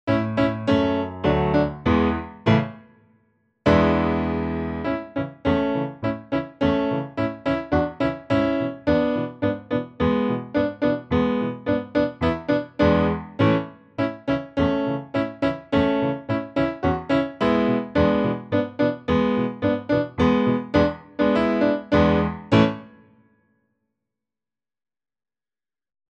Skakal-pes-A-dur.mp3